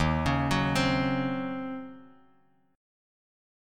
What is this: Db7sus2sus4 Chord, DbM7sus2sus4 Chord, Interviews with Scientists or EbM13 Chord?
EbM13 Chord